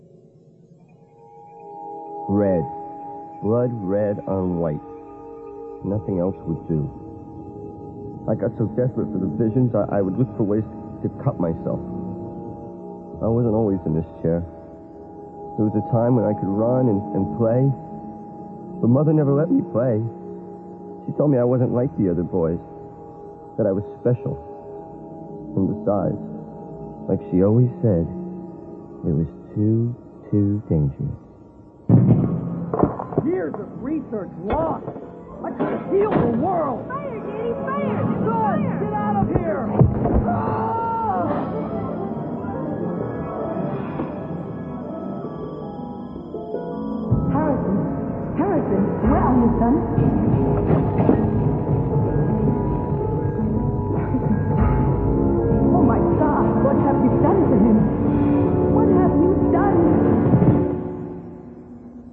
PS: Pay special attention to that "Metallic sound" on this track... Is there a way to fix it?
The metallic sound you hear when turned up loud are compression artifacts from low bitrate audio when it has background in the encoded source.